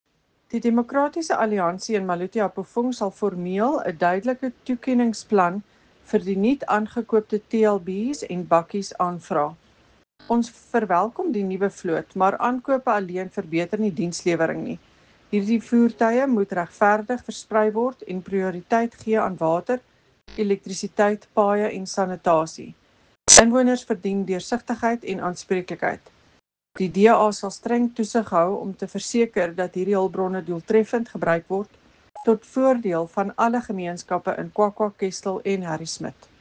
Afrikaans soundbite by Cllr Eleanor Quinta.